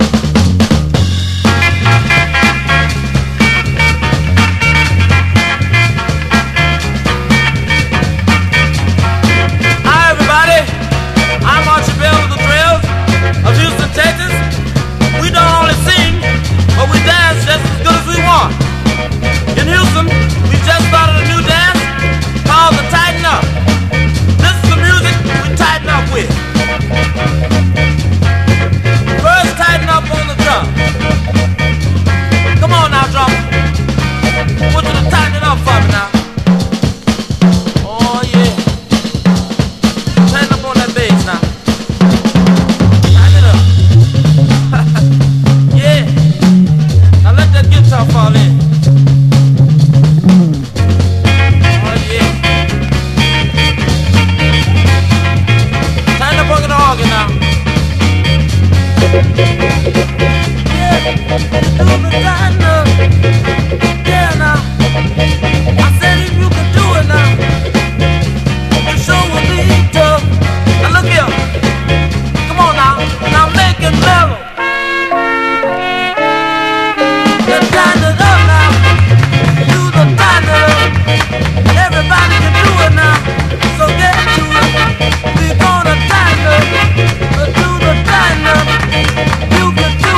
SOUL / SOUL / 70'S～ / GOSPEL / FUNK / SOUL / SOUTHERN SOUL
力強いシスター・ゴスペル・ソウル！